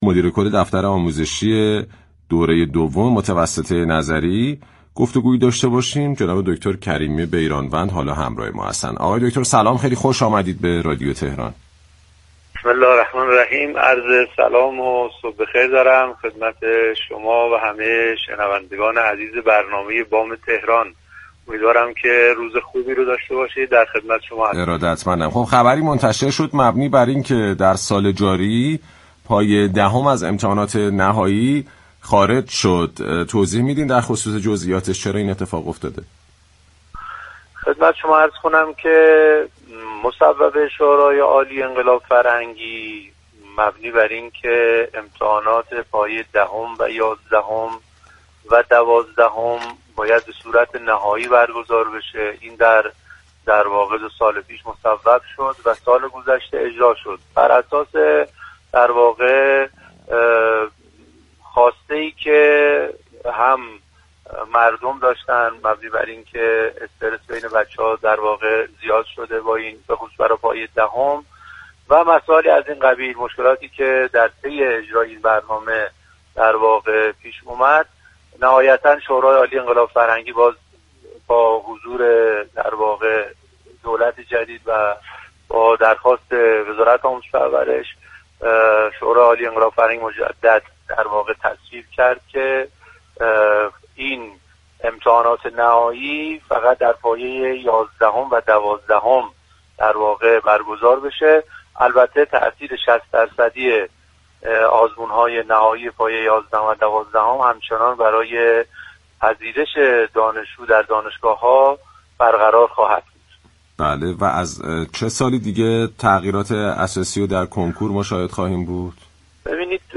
به گزارش پایگاه اطلاع رسانی رادیو تهران، نادر كریمی بیرانوند مدیركل دفتر آموزش دوره دوم متوسطه نظری در گفت و گو با «بام تهران» اظهار داشت: مصوبه شورای عالی انقلاب فرهنگی مبنی بر اینكه امتحانات پایه دهم، یازدهم و دوازدهم به صورت نهایی برگزار شود، سال گذشته اجرا شد؛ اما امسال به دلیل استرس دانش آموزان و مشكلاتی كه در پی اجرای این مصوبه پیش آمد، شورای عال انقلاب فرهنگی با حضور دولت جدید و درخواست ورازت آموزش و پرورش، بار دیگر تصویب كرد امتحان‌های نهایی پایه دهم حذف و در پایه یازدهم و دوازدهم برگزار شود.